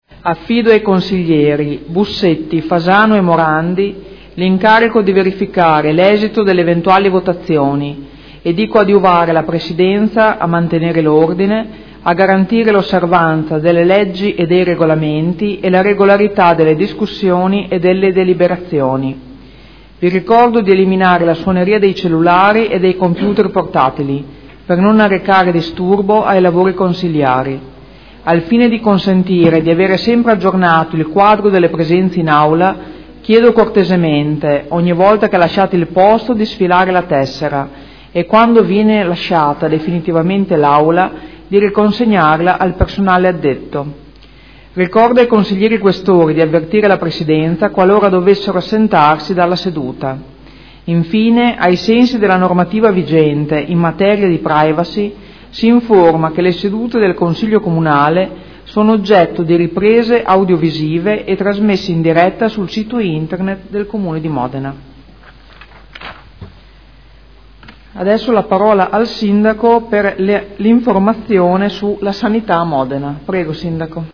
Presidentessa — Sito Audio Consiglio Comunale
Seduta del 09/07/2015 Apre ai lavori del consiglio e passa la parola al Sindaco per una comunicazione.